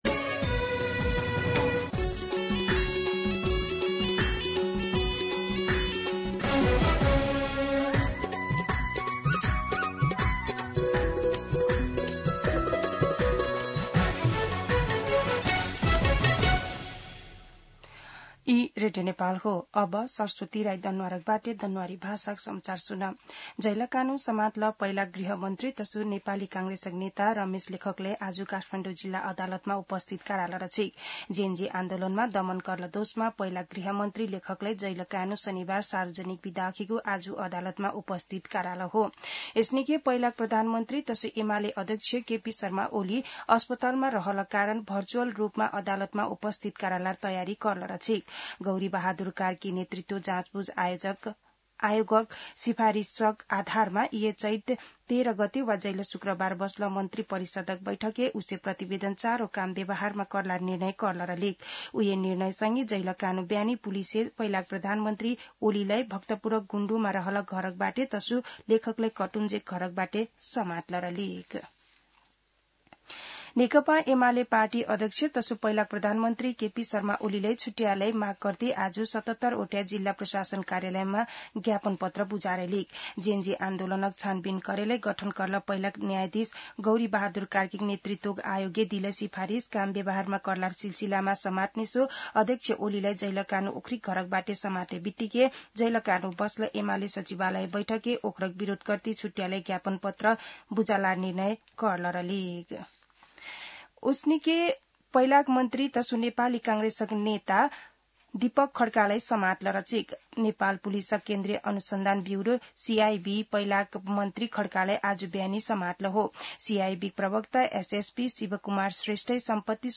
दनुवार भाषामा समाचार : १५ चैत , २०८२